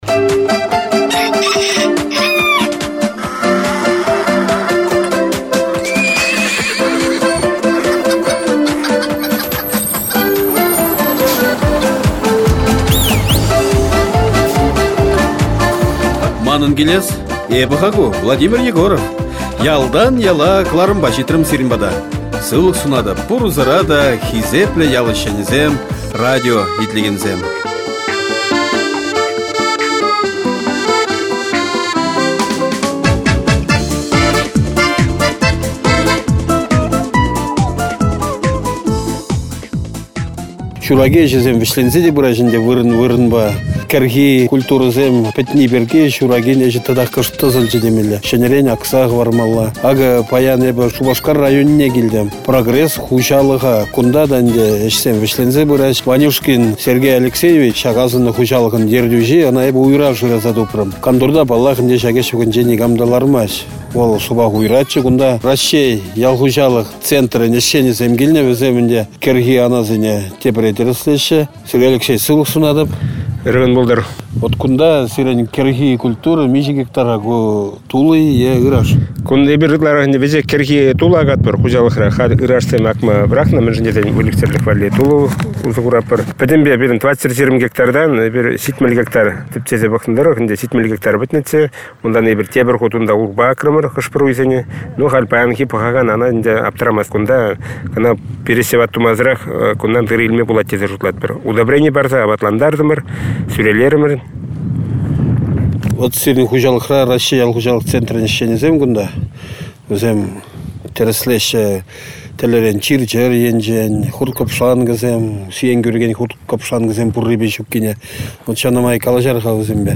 Выступление